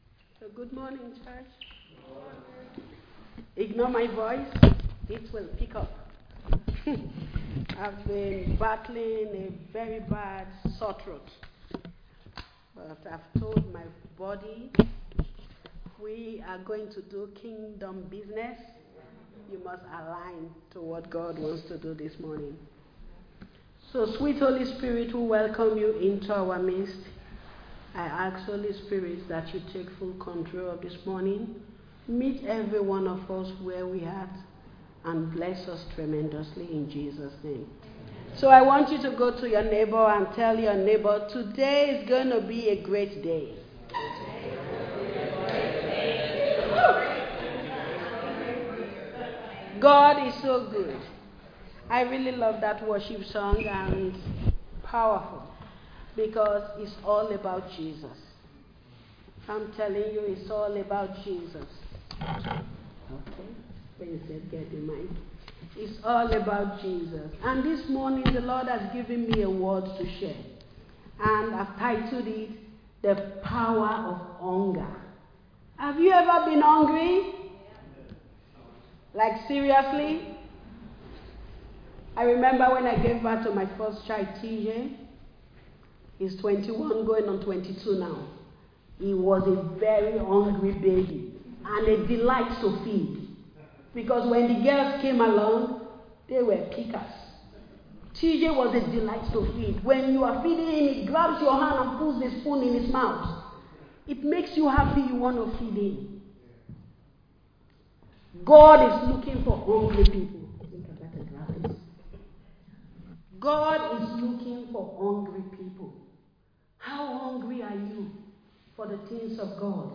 delivers a message on being hungry for more of God Recorded live in Liberty Church on 1 December 2024